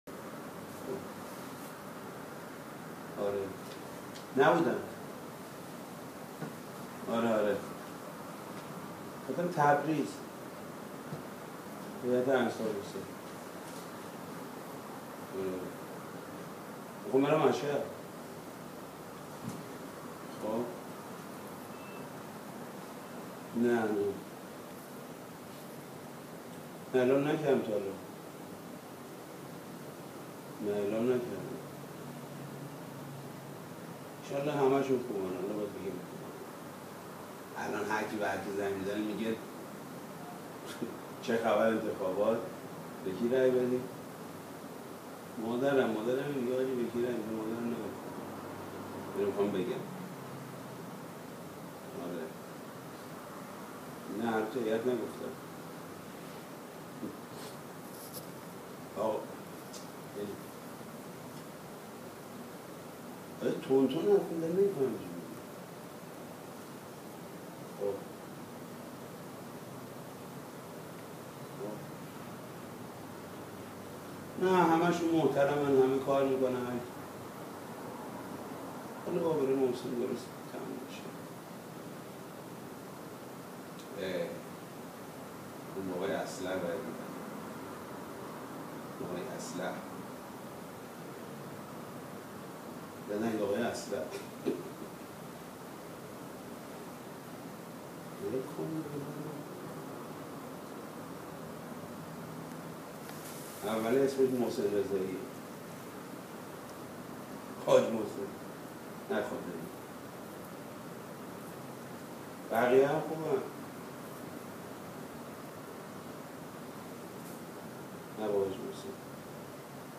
در پی اصرار خبرنگار عماریون حاج محمود کریمی ذاکر با اخلاص و محبوب اهل بیت(علیه السلام) نامزد اصلح خود را معرفی کرد.
در جواب سوال خبرنگار عماریون ، مبنی بر این که کدام آقا محسن؛ ایشان فرمودند همین محسن رضایی؛ مگر چند تا آقا محسن داریم؛ اصلح آقا محسن رضایی می باشند.